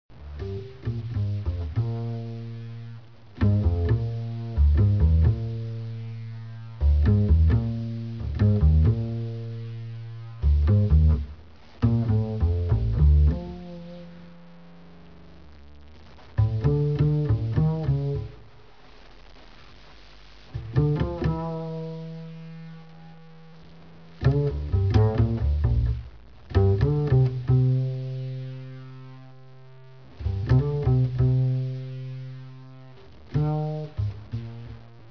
alto & soprano sax